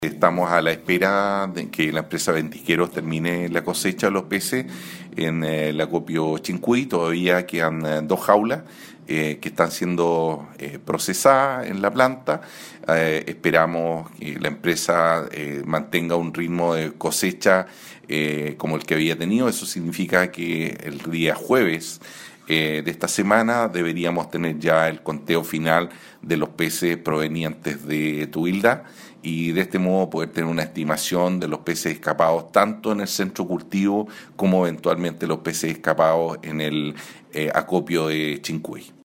Eduardo Aguilera, director regional de Sernapesca, explicó que Ventisqueros, está en plena de cosecha de salmones y se generó la petición de aumento de plazo.
CUÑA-EDUARDO-AGUILERA-.mp3